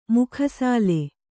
♪ mukha sāle